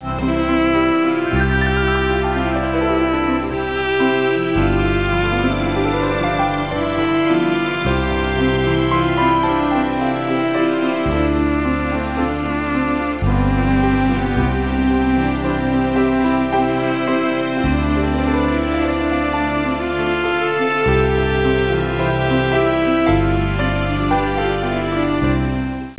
INSTRUMENTAL Epouro Ente Tee (O King of Peace Grant us Peace) [450 KB .wav File] Magd Mariam (Hail to Mary Mother of God) [287KB .wav File]
Instrumental.HailToMary.wav